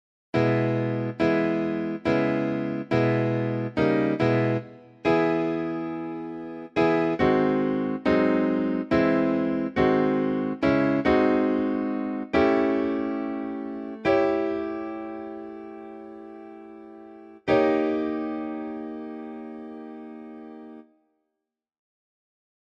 Key written in: E Major
How many parts: 4
Type: Barbershop
All Parts mix: